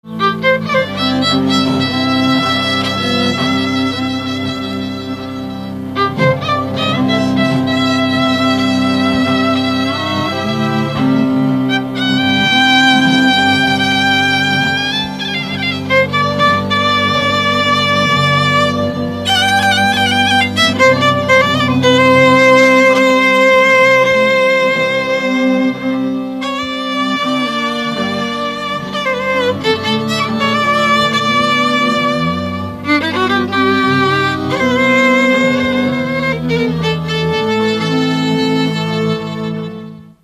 Dallampélda: Hangszeres felvétel
Erdély - Kolozs vm. - Magyarlóna
hegedű
brácsa
kisbőgő
Műfaj: Keserves
Stílus: 3. Pszalmodizáló stílusú dallamok
Kadencia: 5 (b3) 1